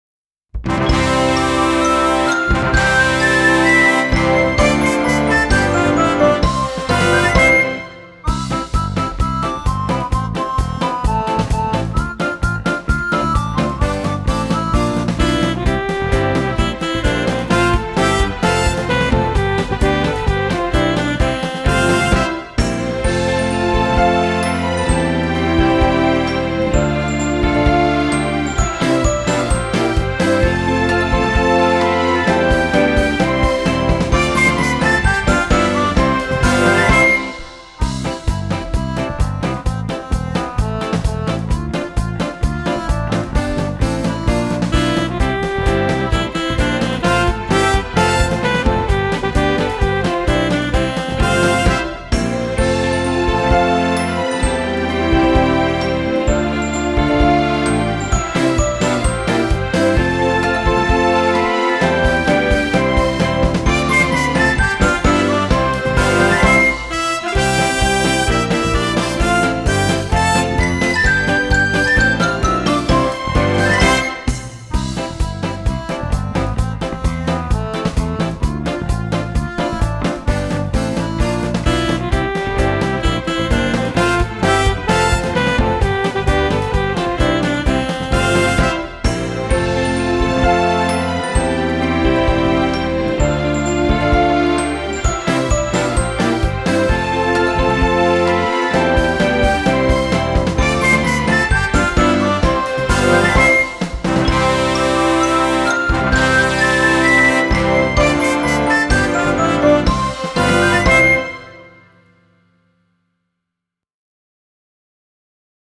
2曲とも、アップテンポで耳馴染みが良く、口ずさみたくなる楽しい曲となっています。
BGMアレンジ1